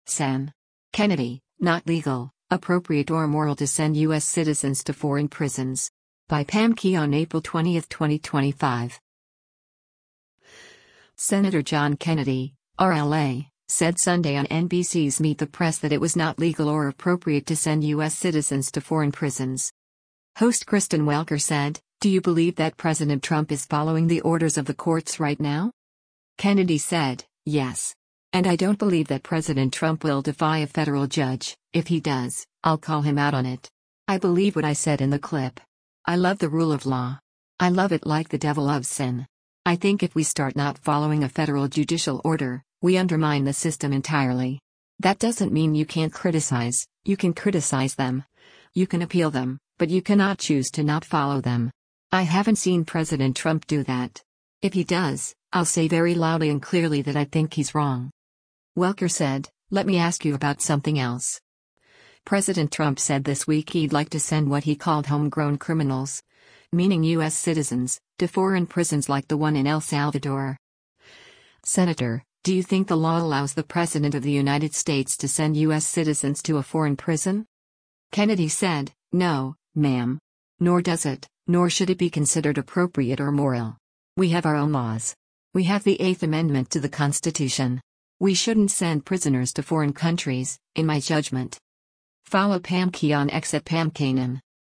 Senator John Kennedy (R-LA) said Sunday on NBC’s “Meet the Press” that it was not legal or appropriate to send U.S. citizens to foreign prisons.